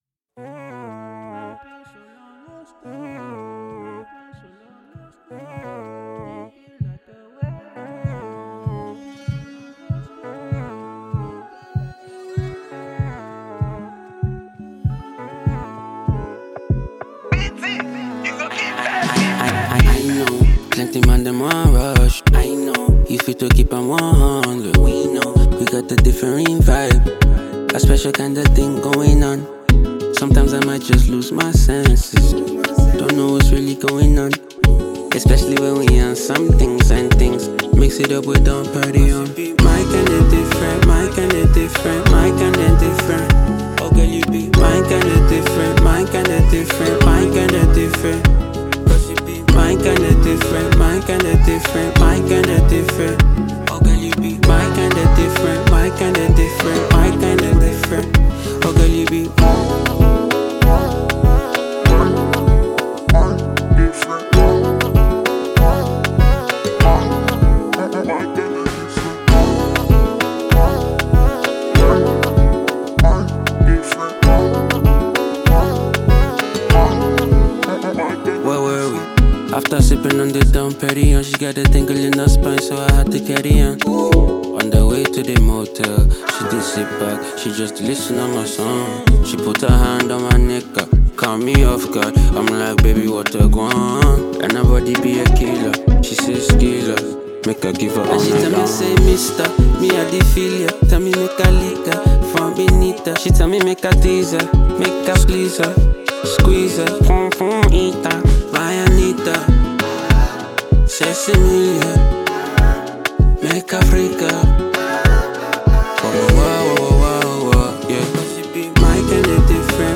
Afropop